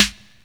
GVD_snr (16).wav